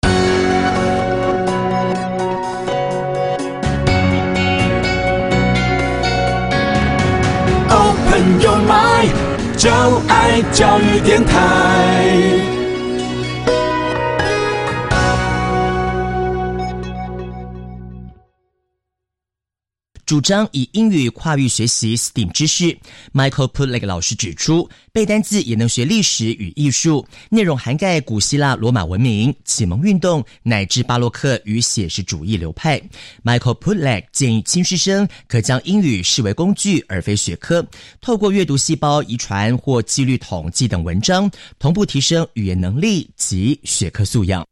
掃描書封QR Code下載「寂天雲」App，即能下載全書音檔，無論何時何地都能輕鬆聽取專業母語老師的正確道地示範發音，訓練您的聽力。